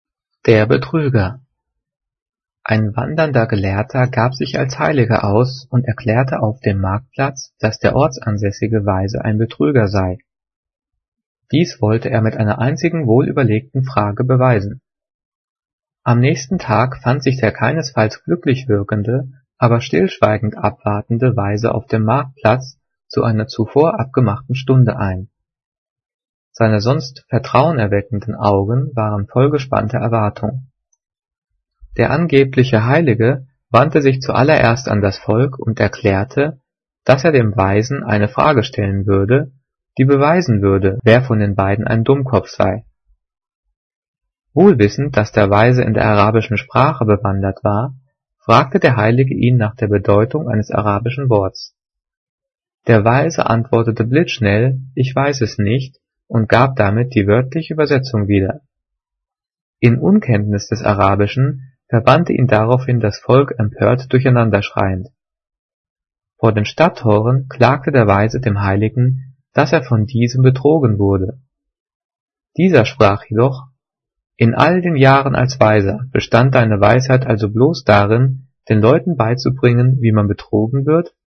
Gelesen:
gelesen-der-betrueger.mp3